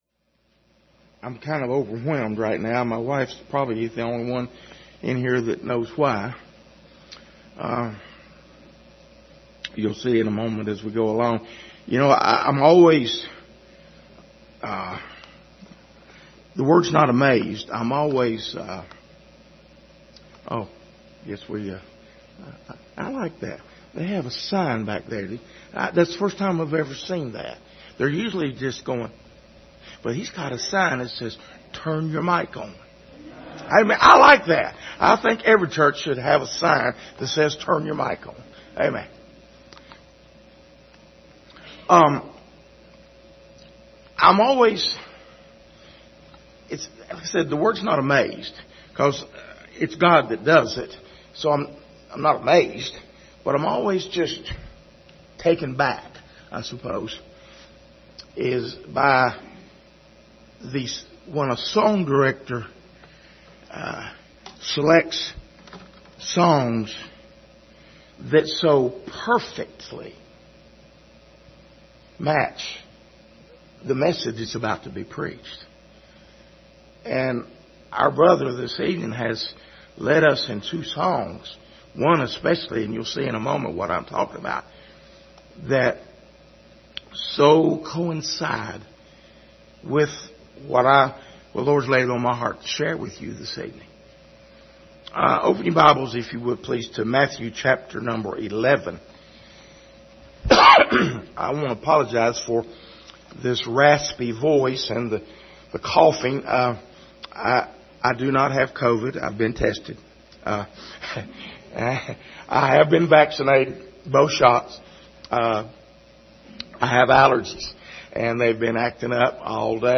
Service Type: Sunday Evening Topics: faith , surrender , trials